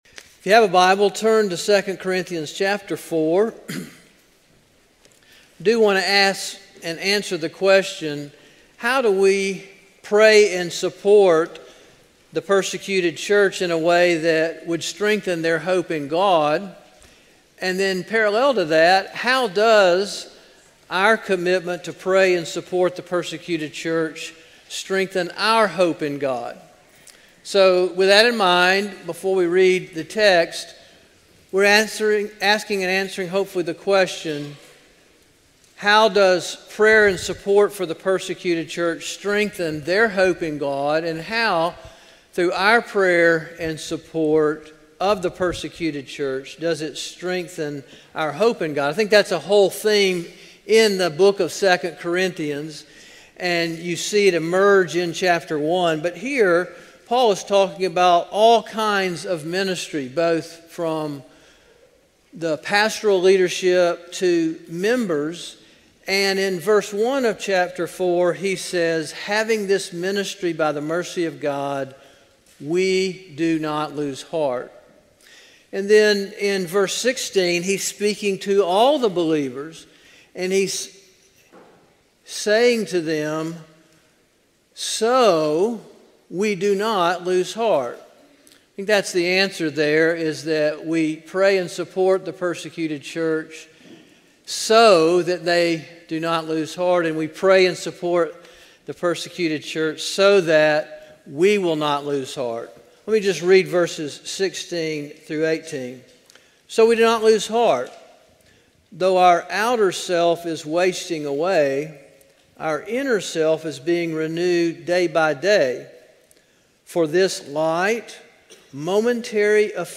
A sermon from the series "Standalone Sermons." 2 Corinthians 4:16-18 November 2, 2025 Evening